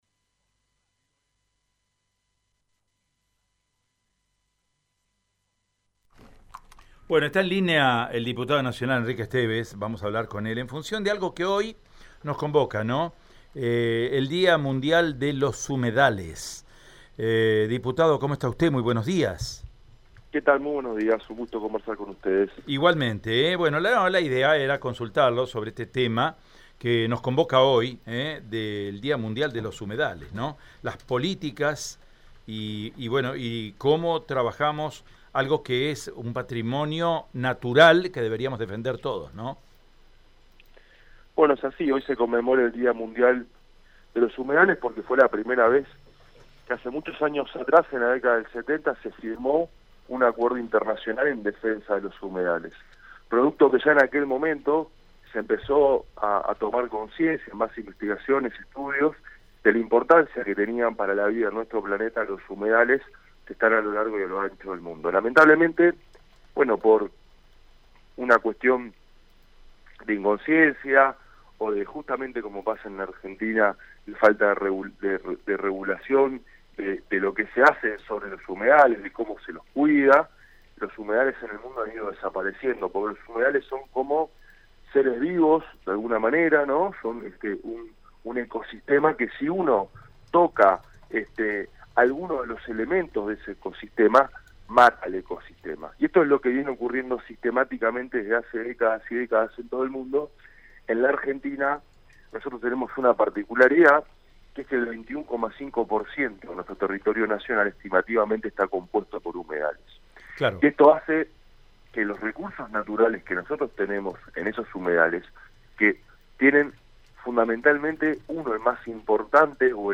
Escuchá la entrevista con Enrique Estevez, diputado Nacional por la provincia de Santa Fe:
Diputado-Nacional-Enriquee-Estevez-En-el-DiaMundialdelosHumedales.mp3